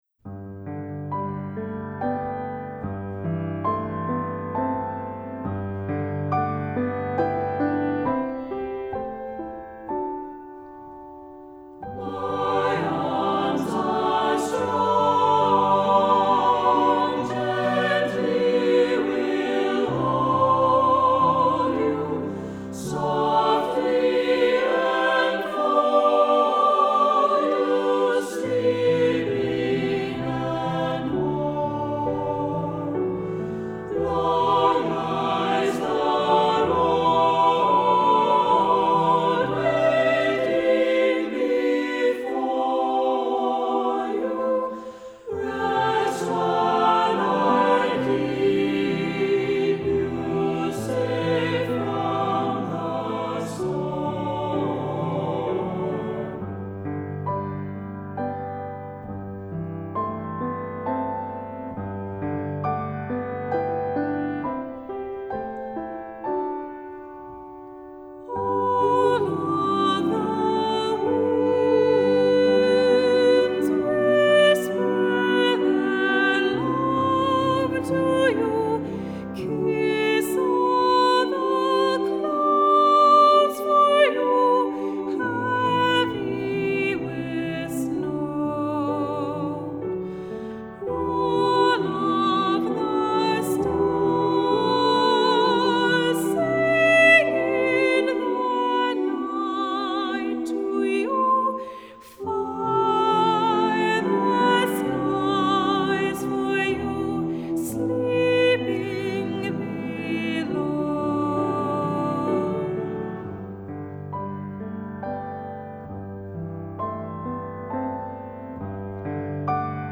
Accompaniment:      Piano
Music Category:      Choral
Set to a tempo steady but unhurried